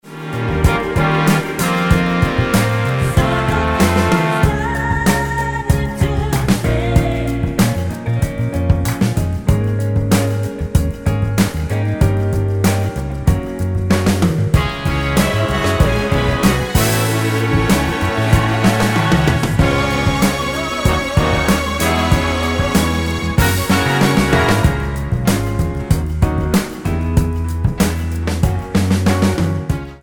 Tonart:C# mit Chor